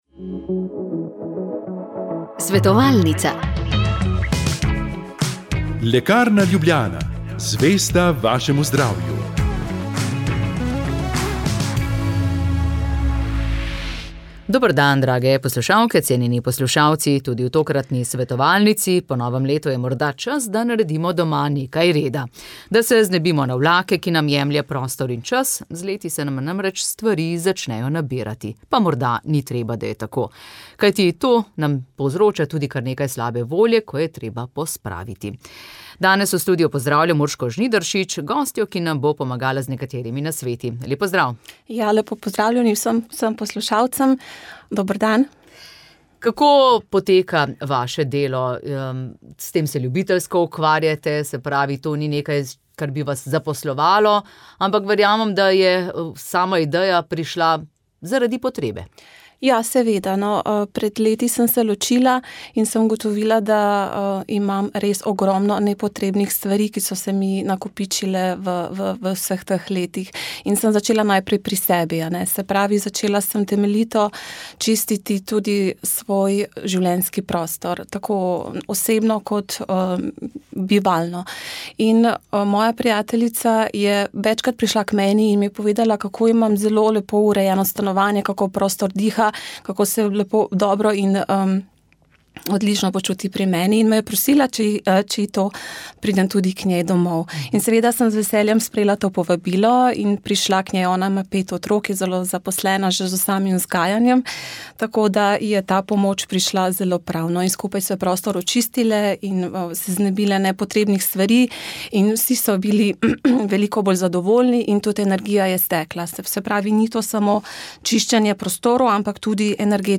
smo se pogovarjali z gozdno vodnico